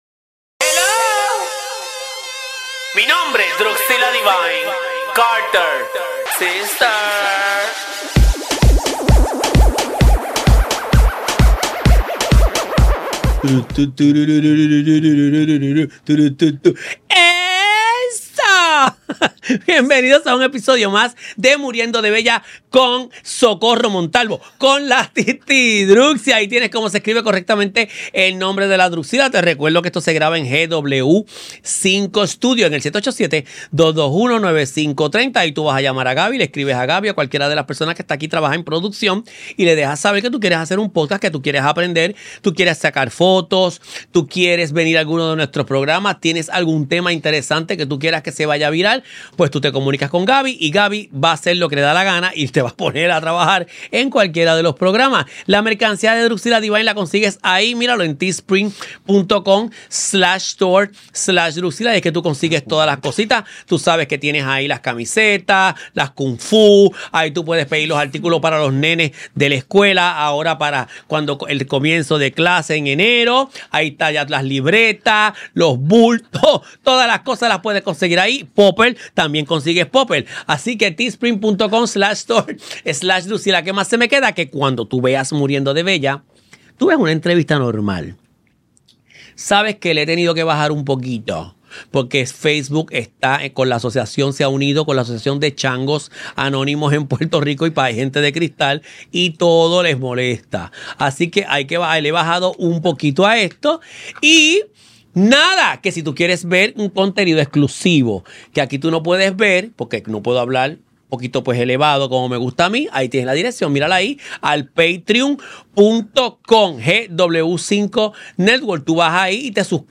Grabado en los estudios de GW-Cinco y somos parte del GW5 Network.